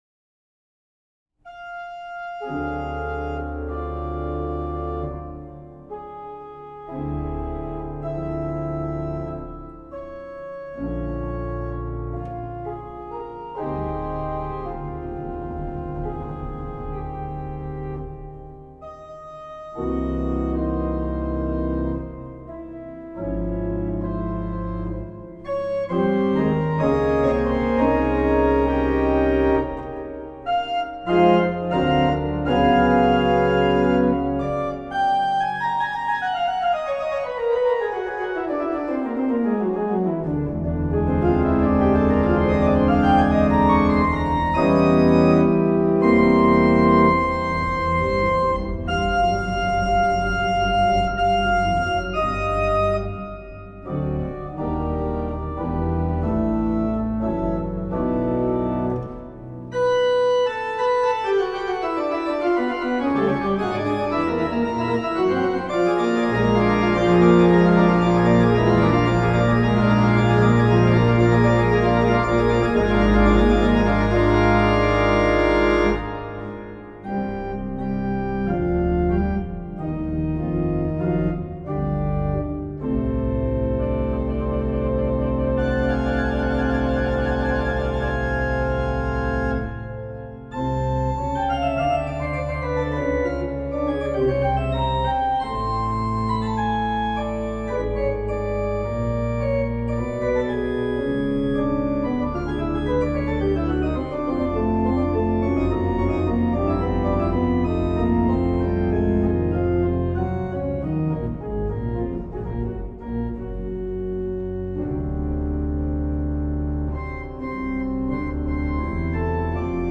Orgelwerke